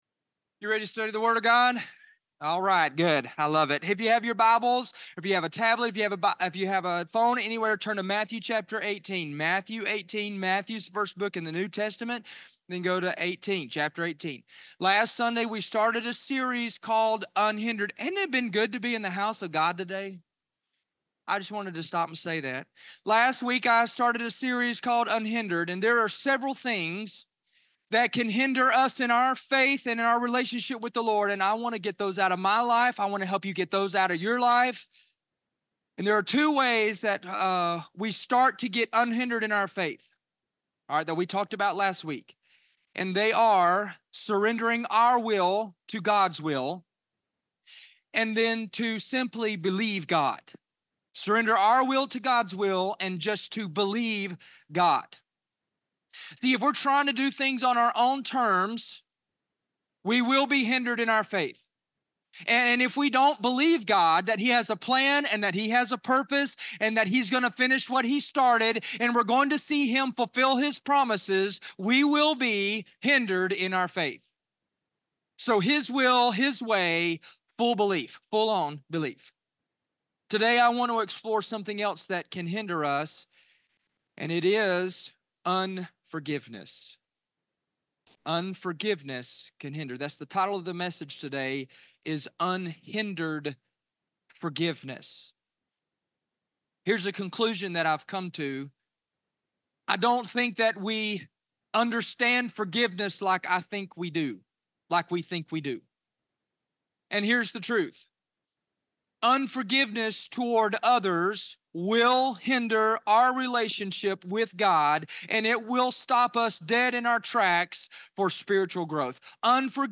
Unhindered Forgiveness - Unhindered Sermon Series Week 2